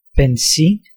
Ääntäminen
US : IPA : [ˈɡæs.ə.ˌlin]